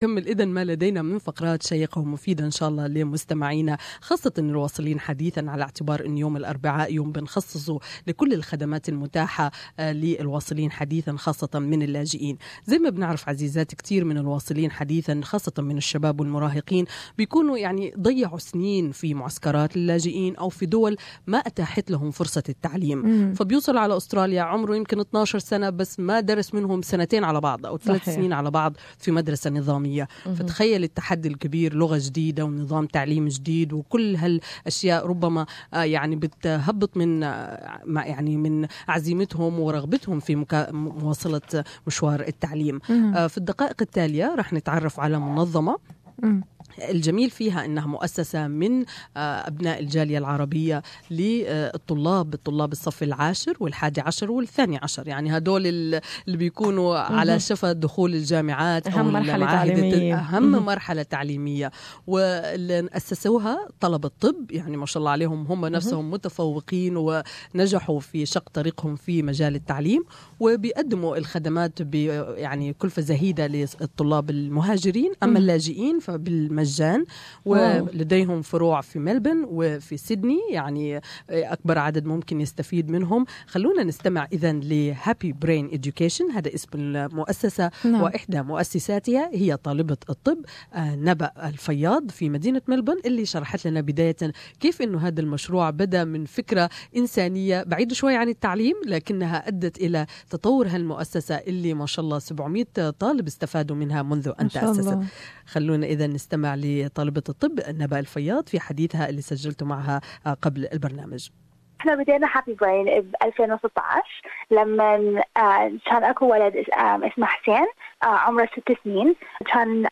What started as a fundraising effort to the save the life of one child has become a life changing experience for hundreds of young people! More in this interview